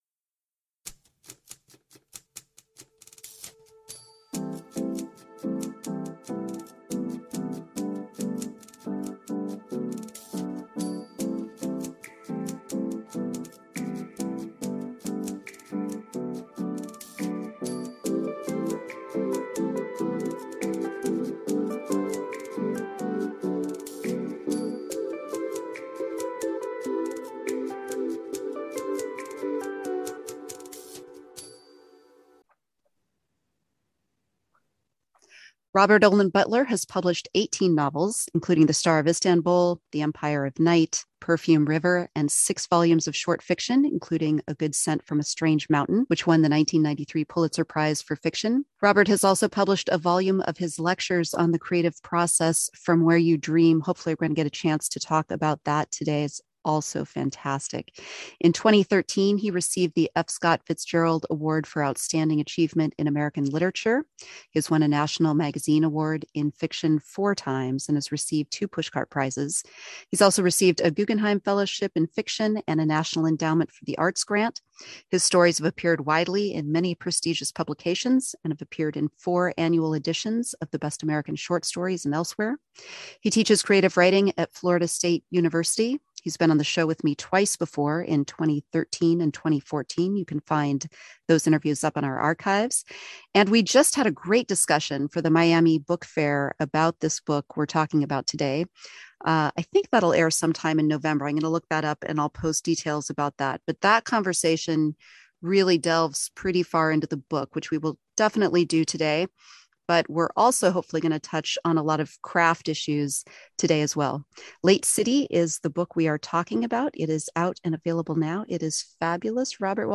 Dreamstorming Interview with Robert Olen Butler (for Butler on dreamstorming, go to 35:45).